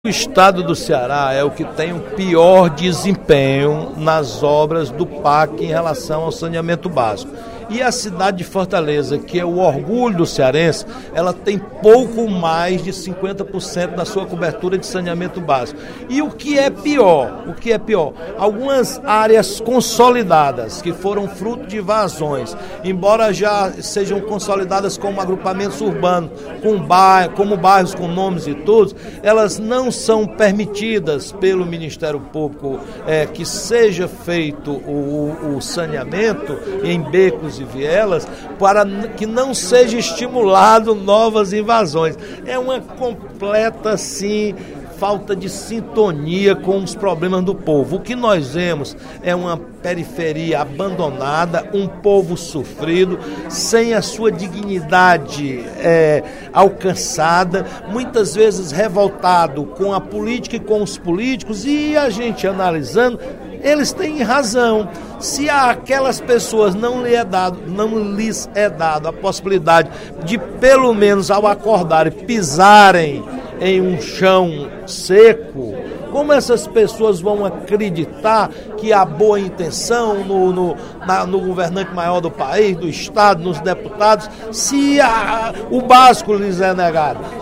No primeiro expediente da sessão plenária desta terça-feira (18/03), o deputado Roberto Mesquita (PV) alertou para a necessidade de investimentos na área de saneamento básico no Estado.